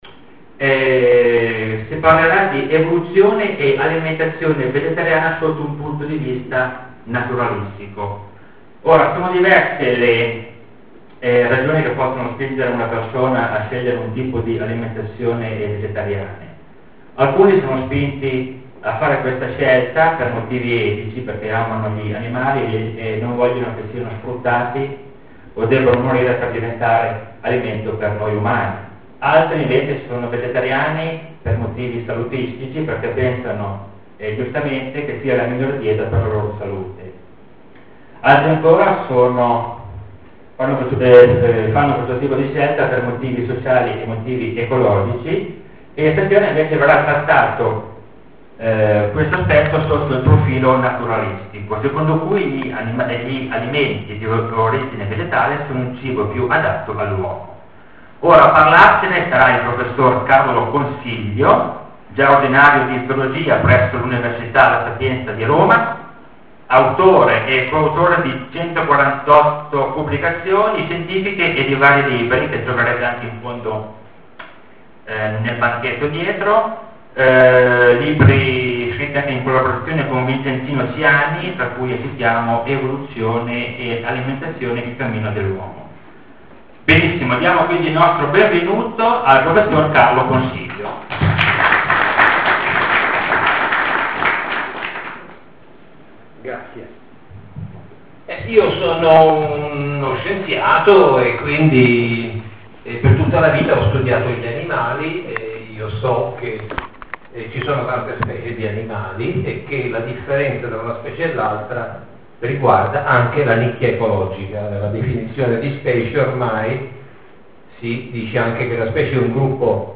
Ciclo di conferenze: ''Animali e Umani'' 4, novembre 2005 - AgireOra Alessandria